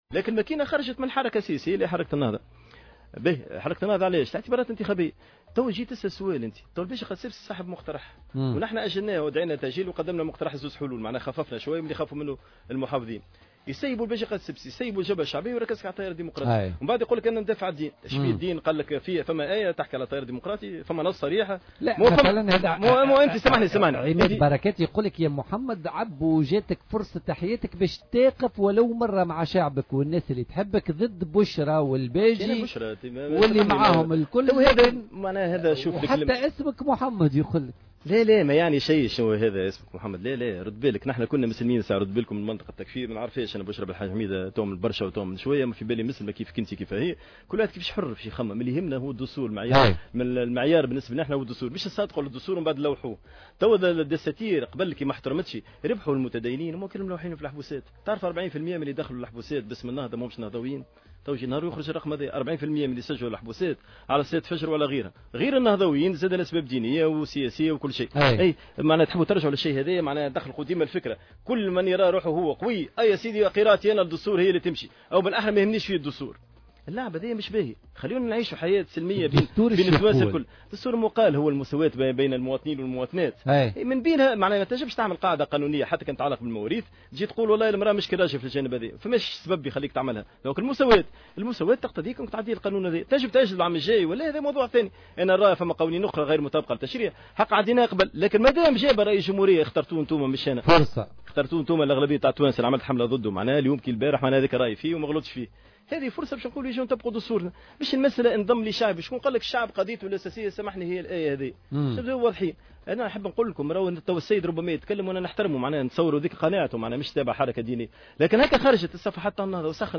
وقال عبّو، ضيف بولتيكا اليوم الخميس، إن حركة النهضة "زايدت" على موقف التيار من وجهة نظر دينية، والحال أن التيار لم يزايد على تصويتها للتخفيض في أسعار الخمور في إحدى الجلسات العامة بمجلس نواب الشعب سنة 2016، معبرا في الآن ذاته عن استغرابه من الحملة التي تُشن ضد حزبه، بدلا من شنها رئيس الجمهورية الباجي قائد السبسي صاحب المقترح، لأسباب "يعرفها الجميع" حسب تعبيره.